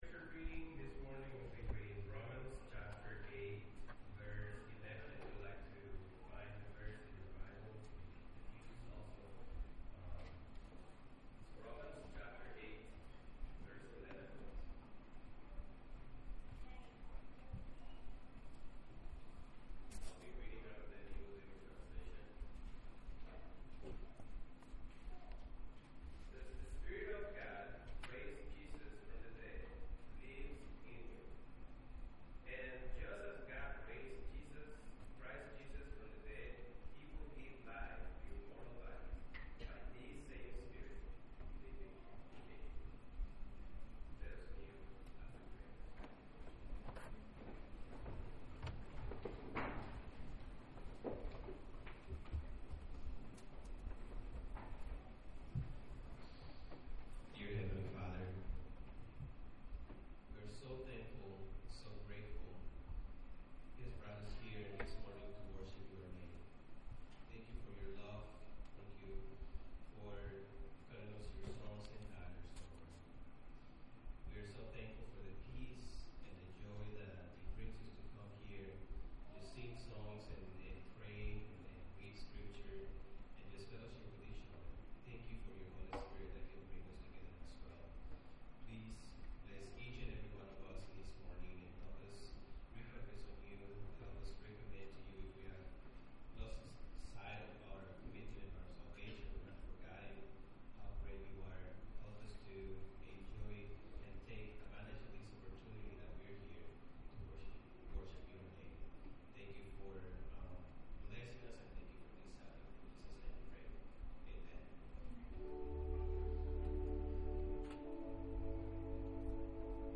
The Spoken Word